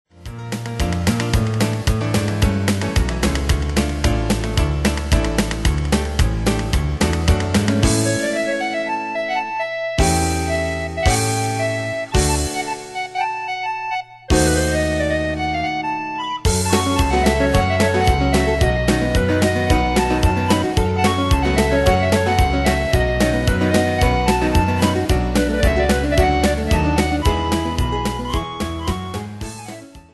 Danse/Dance: Charleston Cat Id.
Pro Backing Tracks